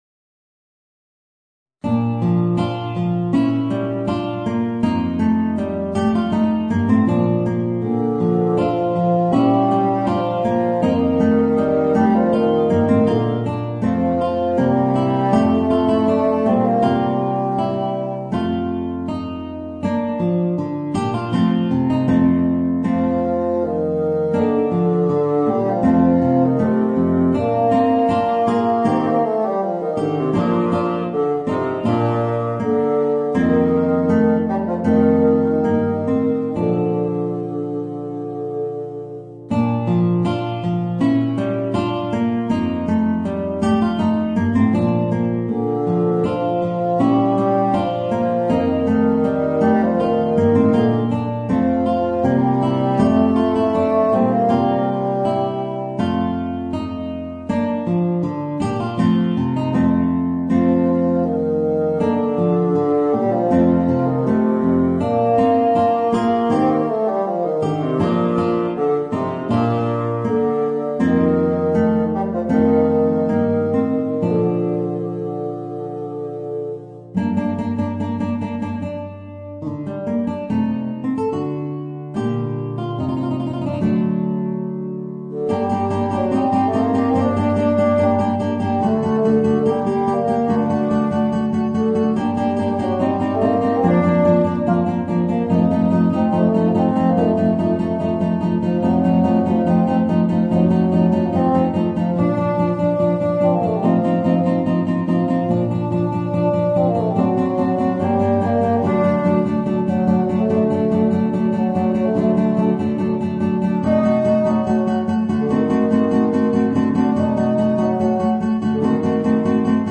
Voicing: Bassoon and Guitar